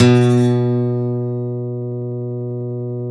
Index of /90_sSampleCDs/Roland - Rhythm Section/GTR_Steel String/GTR_ 6 String
GTR 6-STR30S.wav